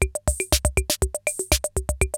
CR-68 LOOPS4 4.wav